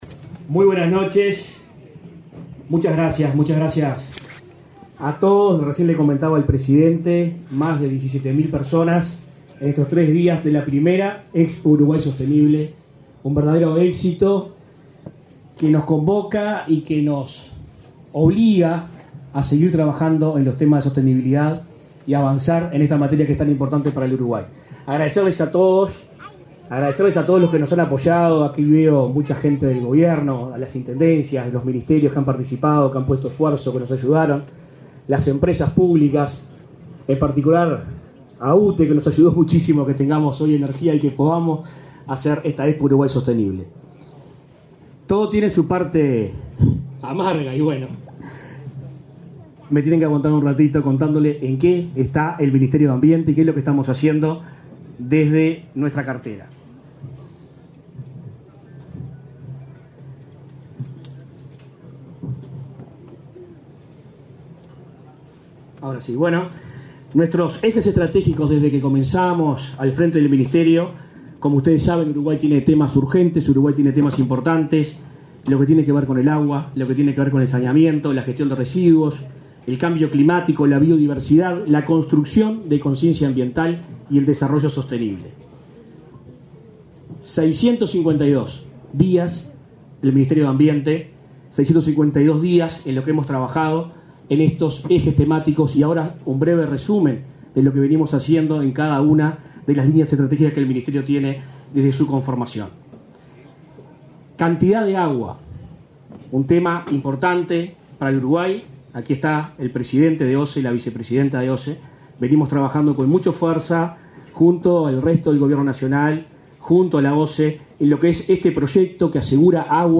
Palabras del ministro de Ambiente, Adrián Peña
En el acto, se expresó el ministro de Ambiente, Adrián Peña.